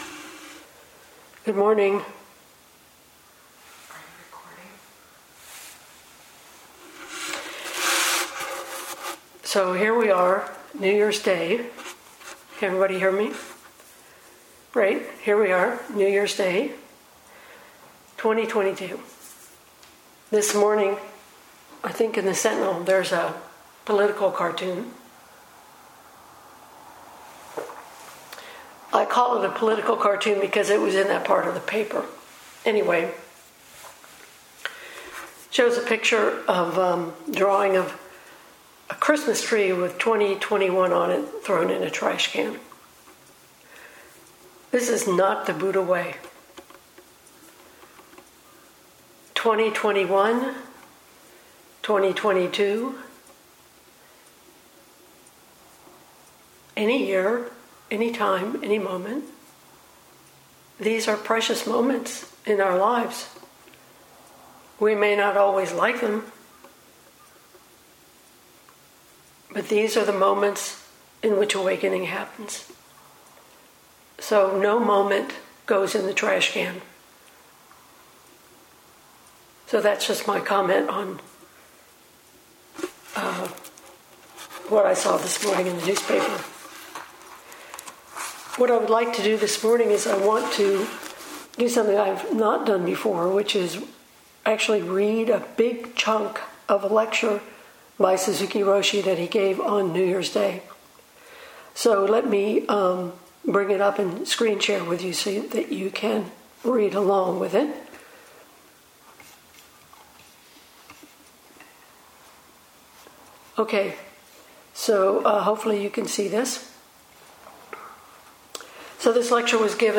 2022 in Dharma Talks